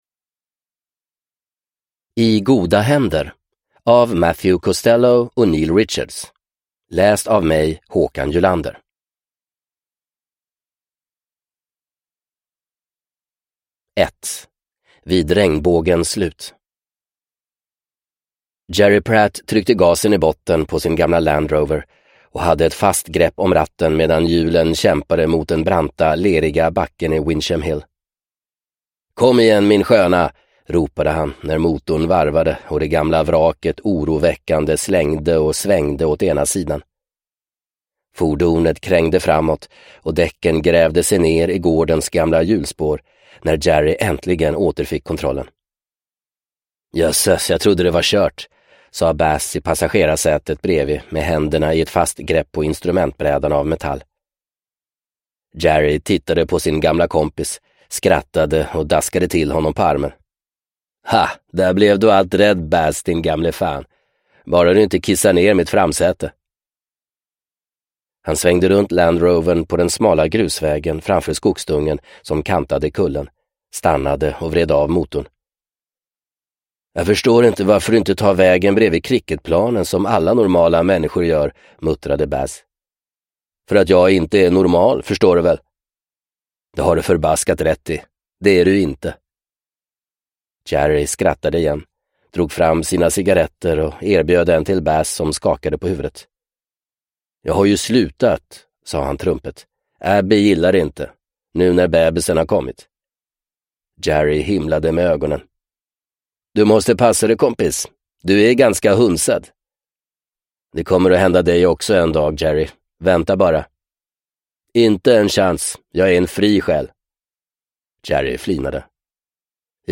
I goda händer – Ljudbok – Laddas ner